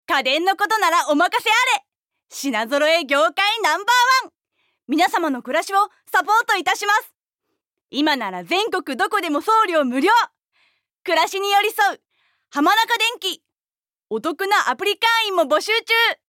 ナレーション1